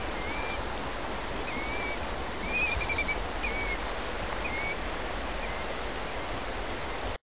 amerikanskij-dvupolosij-ili-kriklivij-zuek-charadrius-vociferus.mp3